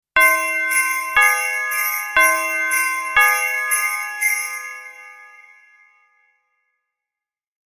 Warm Christmas Chime Sound Effect
Description: Warm Christmas chime sound effect. Classic “ding-dong” doorbell tone mixed with festive sleigh bells for a cheerful Christmas touch. This joyful sound perfectly captures the holiday spirit at your front door.
Warm-christmas-chime-sound-effect.mp3